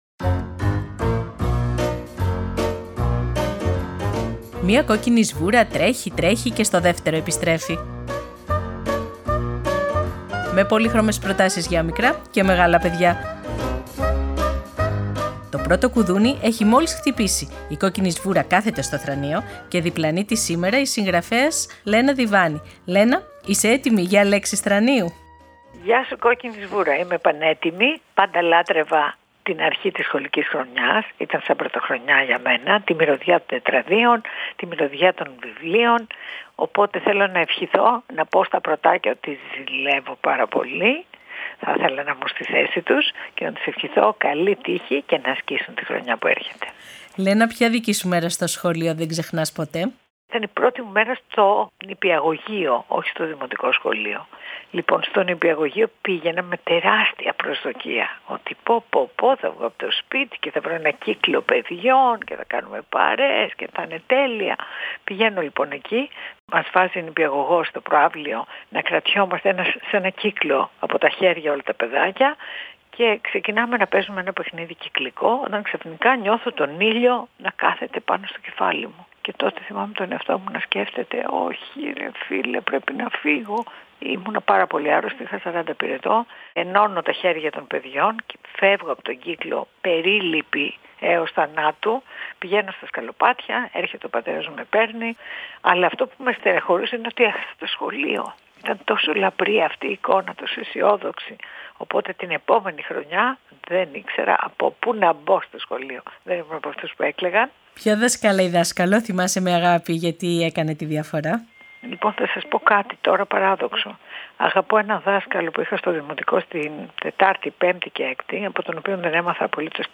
Επιμέλεια – Παρουσίαση